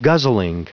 Prononciation du mot guzzling en anglais (fichier audio)
Prononciation du mot : guzzling